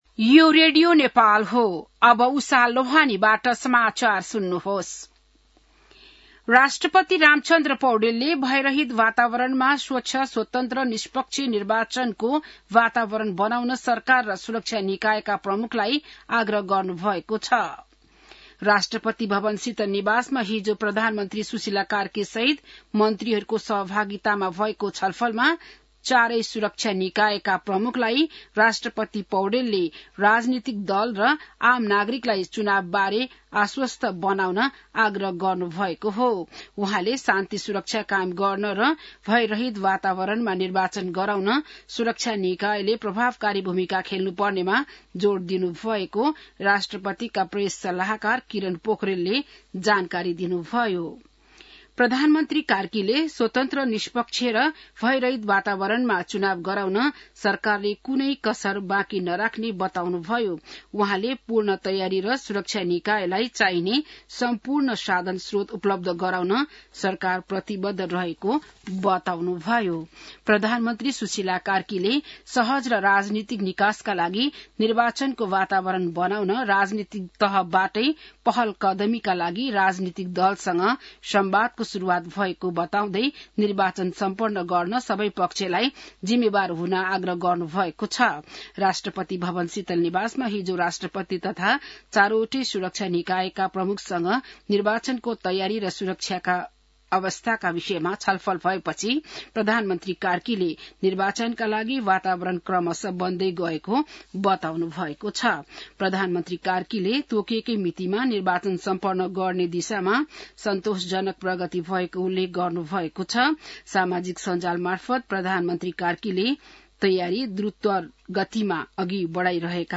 बिहान १० बजेको नेपाली समाचार : १८ पुष , २०२६